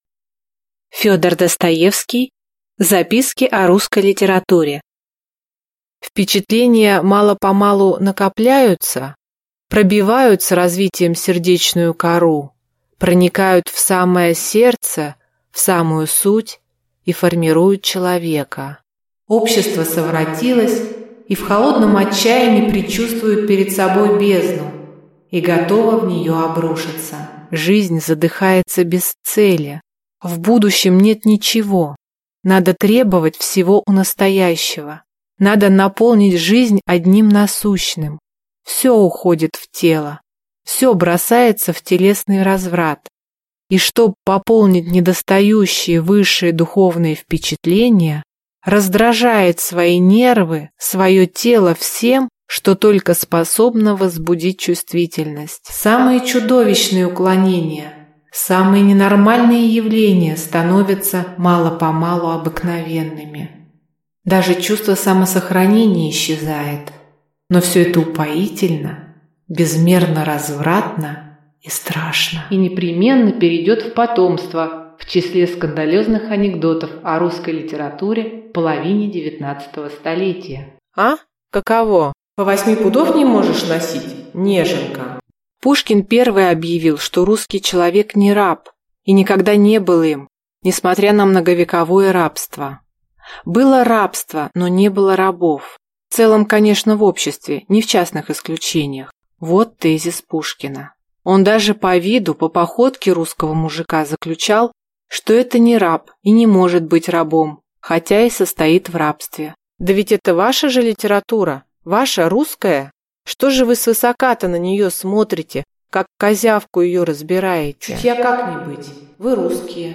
Аудиокнига Записки о русской литературе | Библиотека аудиокниг